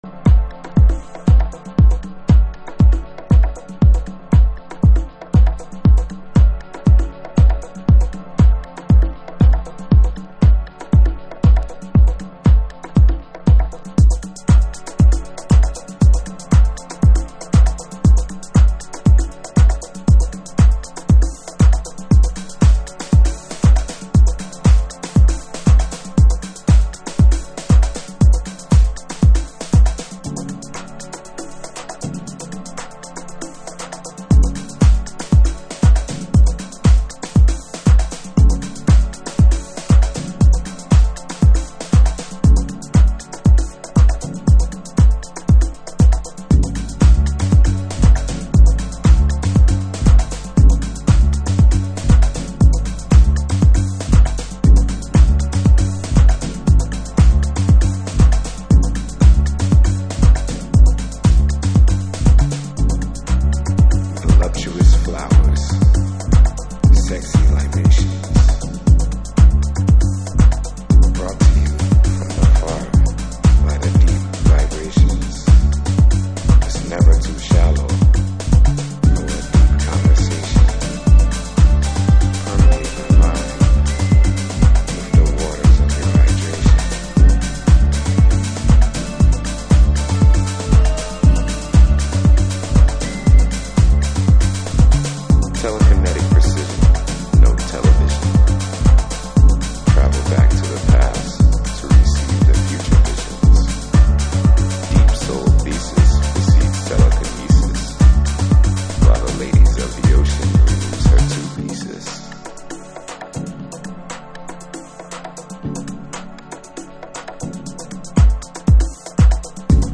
TOP > House / Techno > VARIOUS